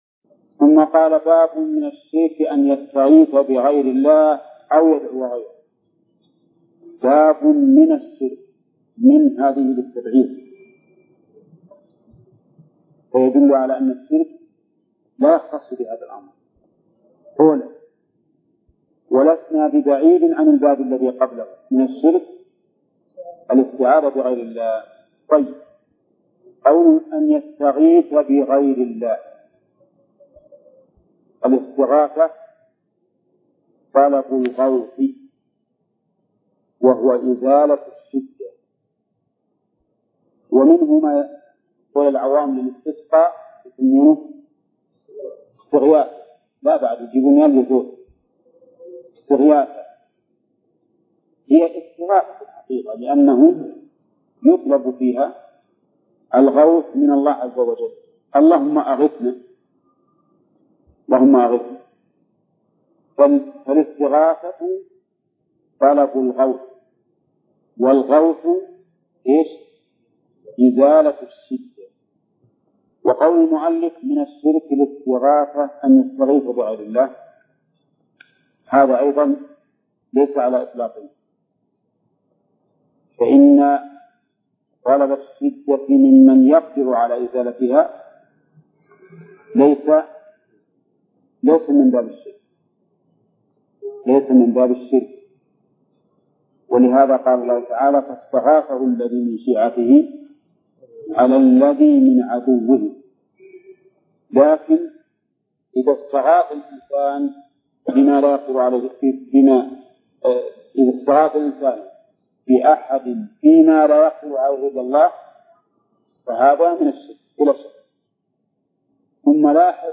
درس : 13 : من صفحة: 260، قوله: باب من أن يستغيث بغير الله أو يدعو غيره، إلى صفحة: 283، قوله: باب قول الله تعالي: (أَيُشْرِكُونَ مَا لا يَخْلُقُ شَيْئاً وَهُمْ يُخْلَقُونَ.